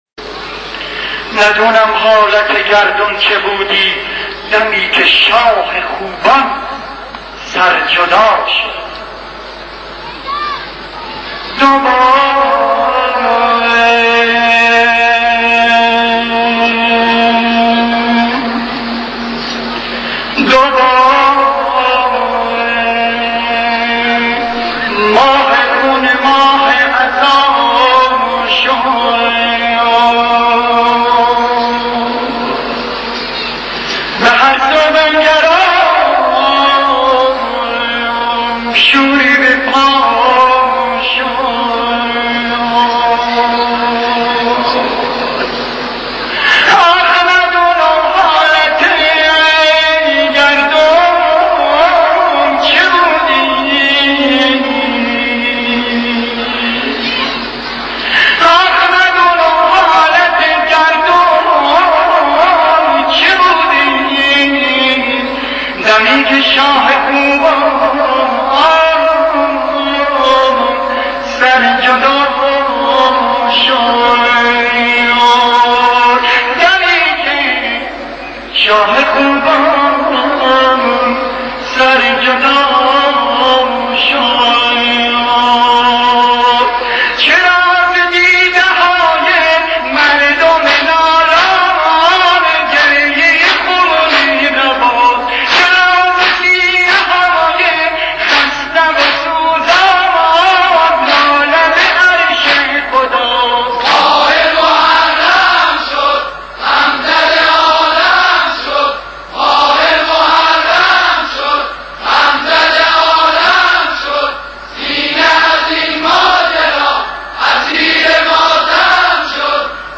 با نوای گرم نوحه خوان اسطوره ای ایران؛